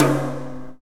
Index of /90_sSampleCDs/Roland L-CDX-01/KIT_Drum Kits 6/KIT_Parched Kit
TOM REGGAE03.wav